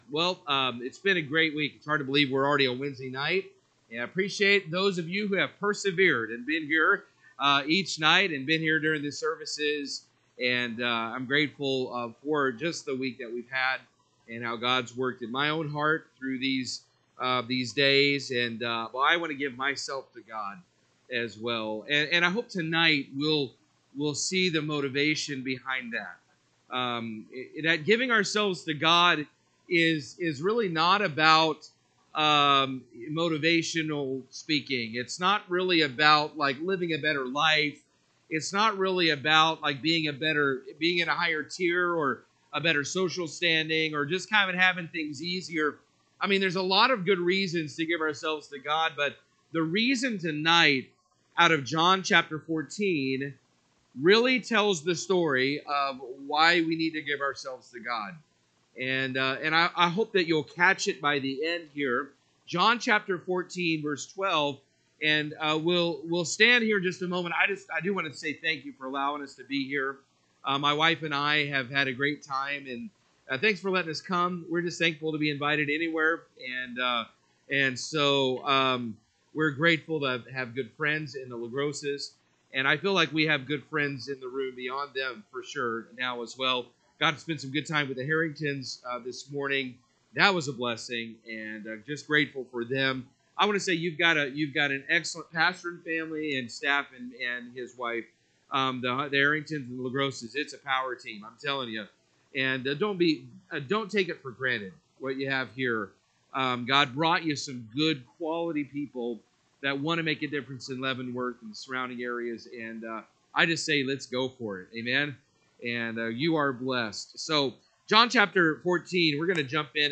Mission Revival 2025 , Wednesday Evening